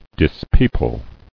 [dis·peo·ple]